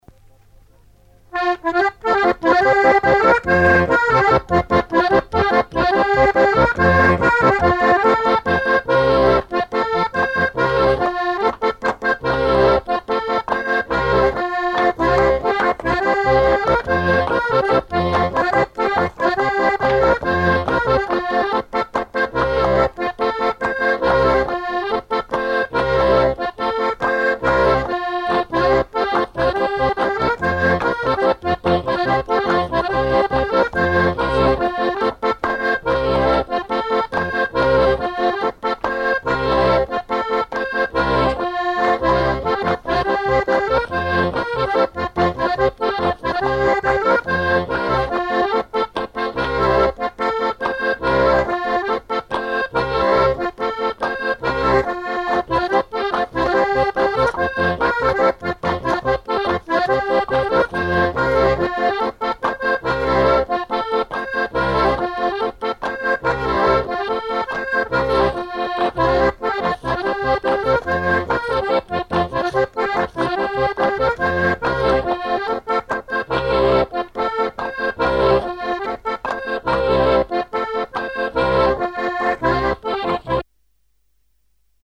Challans ( Plus d'informations sur Wikipedia ) Vendée
Chants brefs - A danser
danse : polka piquée
Chansons traditionnelles